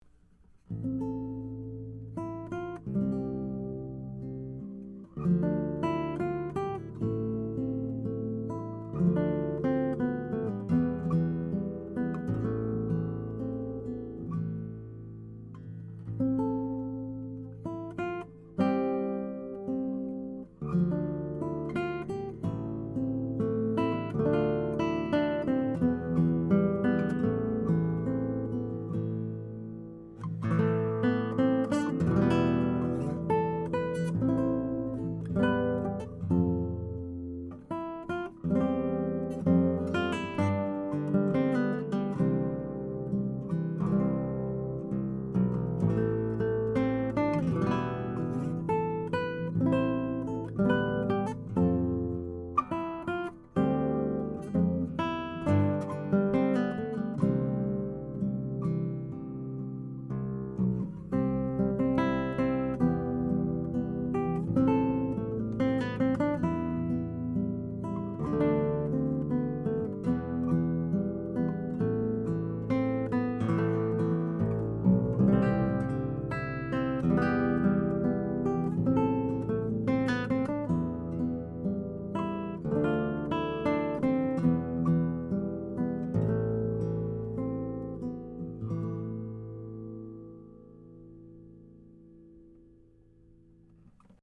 Recorded with a couple of prototype TAB Funkenwerk / German Masterworks CG•OA-1 condenser mics into a Trident 88 recording console using Metric Halo ULN8 converters:
(English Renaissance)